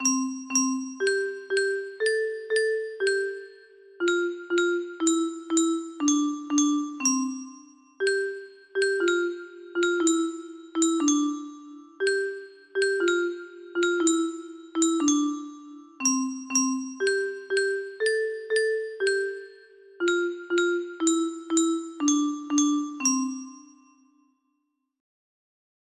작은별 music box melody